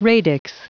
Prononciation du mot radix en anglais (fichier audio)
Prononciation du mot : radix